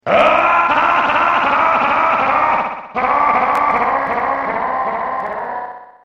hear what he laughs like.